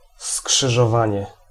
Ääntäminen
US : IPA : [ˈkrɑs.ɪŋ]